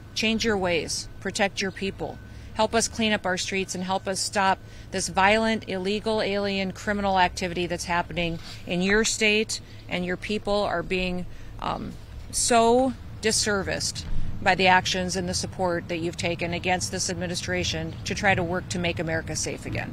Noem spoke outside the Governor’s Mansion, calling on Governor JB Pritzker to end the policies she claims have resulted in additional crimes committed by people in the country illegally: